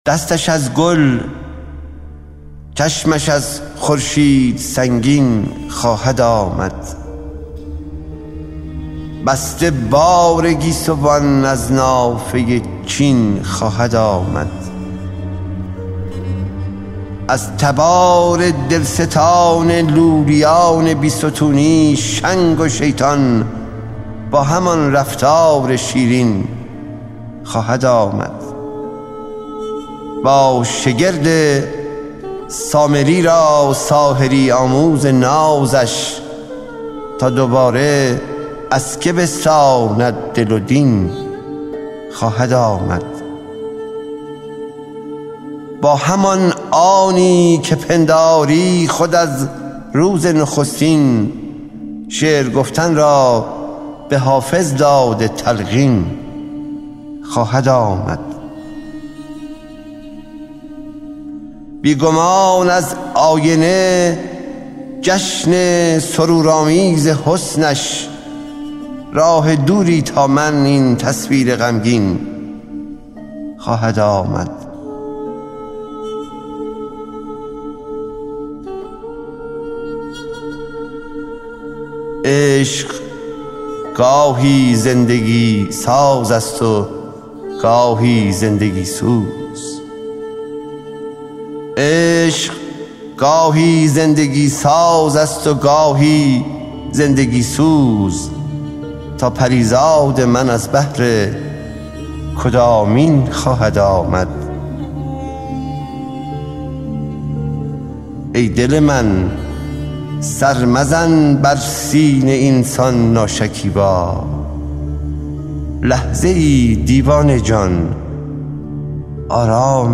دانلود دکلمه دستش از گل ، چشمش از خورشید سنگین خواهد آمد با صدای «حسین منزوی»
شاعر :   حسین منزوی